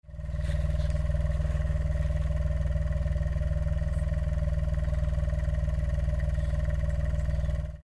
それぞれの排気音を収録してきたのでどうぞ・・
柿本改のGTbox06&S（アイドリング）
copen_la400-kakimoto_idling.mp3